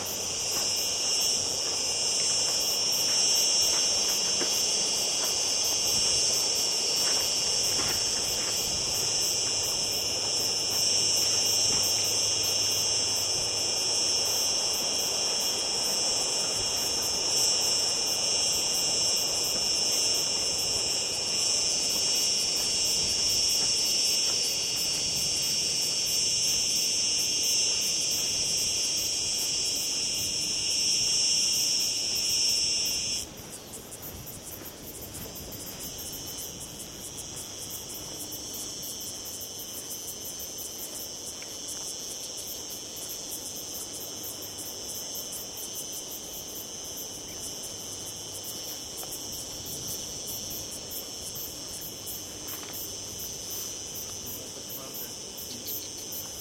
描述：单一的雷声（很近，但有点失真），然后雨滴落在金属板上，还有更远的雷声。森海塞尔ME62(K6)在西班牙南部多纳纳沼泽地的Jose Antonio Valverde访问中心
标签： 道纳拿 现场记录 性质 夏天
声道立体声